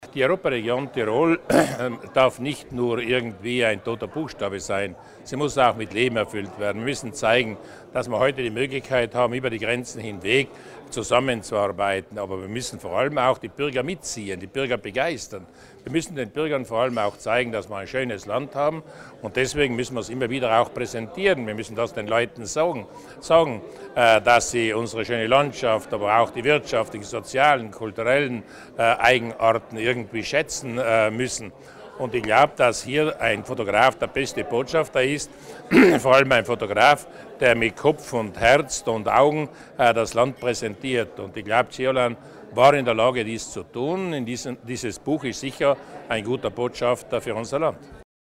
Landeshauptmann Durnwalder zum Wert des Buches "Terra in Montanis"